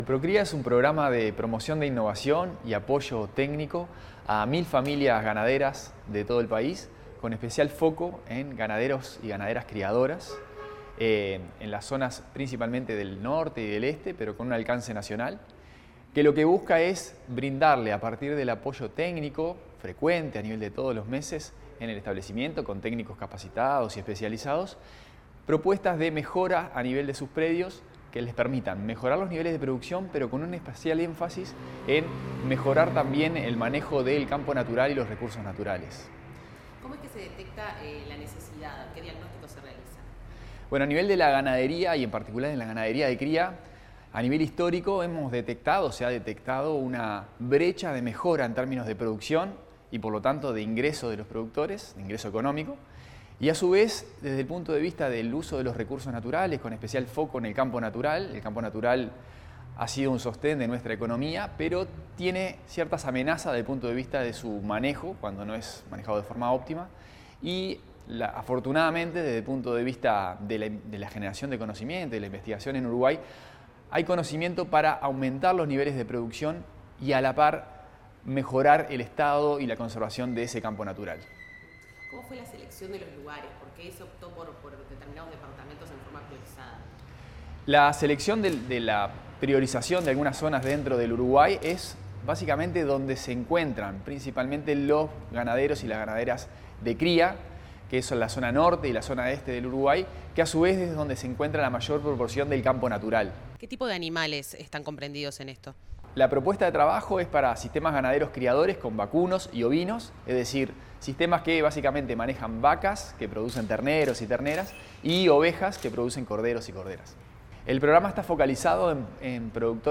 Declaraciones del presidente del IPA, Santiago Scarlato